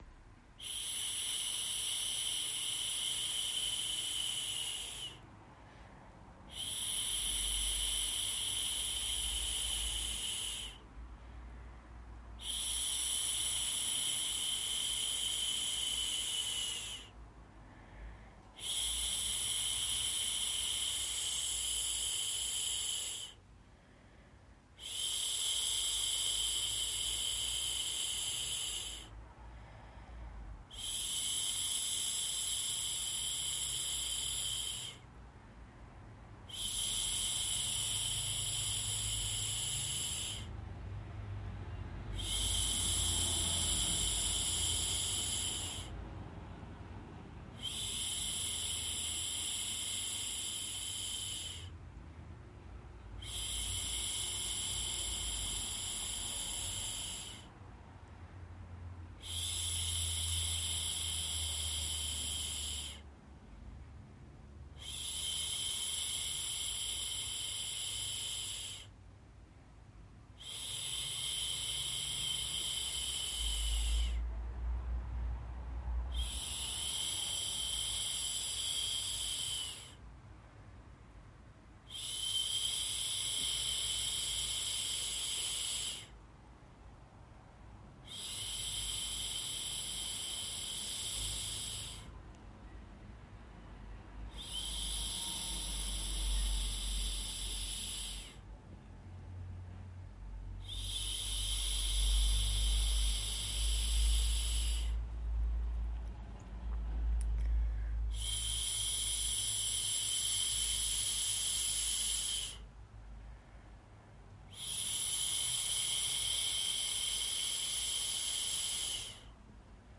Real human voice
Shhh….mp3